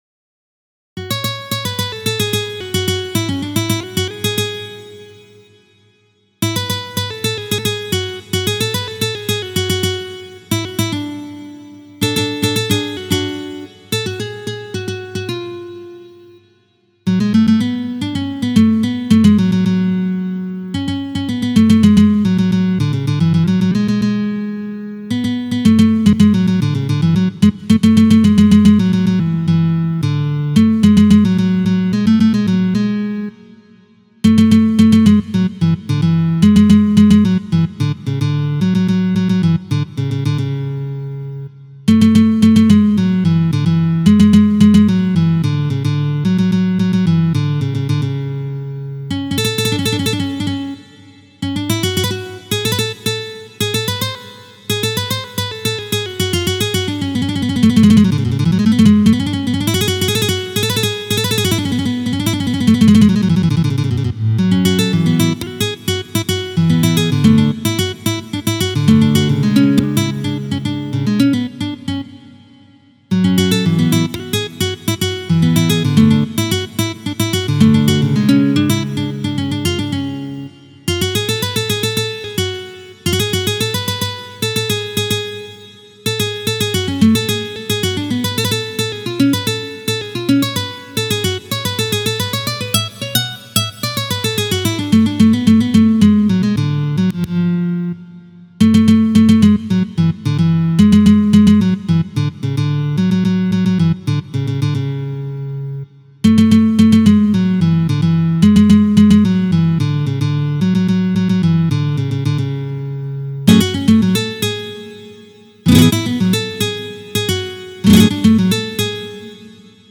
گیتار
تنظیم شده برای گیتار به همراه تبلچر